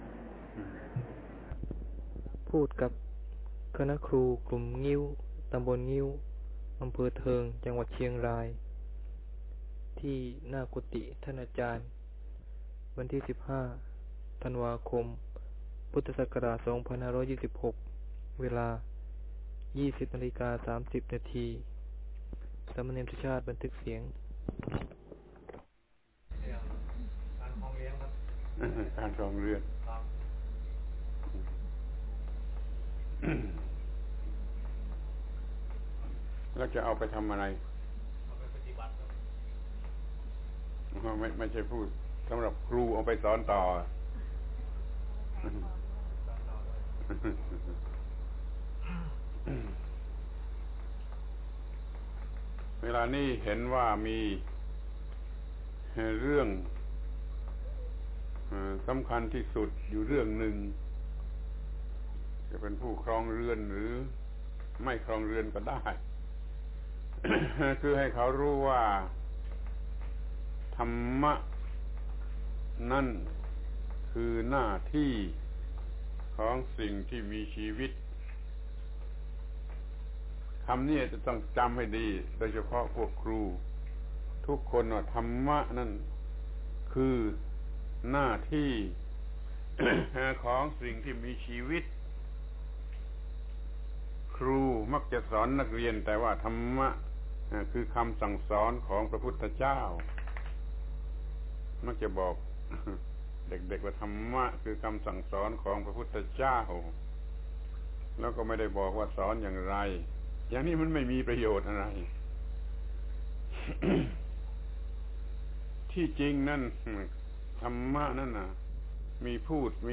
พระธรรมโกศาจารย์ (พุทธทาสภิกขุ) - พูดกับคณะครูกลุ่มงิ้ว ต.งิ้ว อ.เทิง จ.เชียงราย เรื่อง ธรรมะคือหน้าที่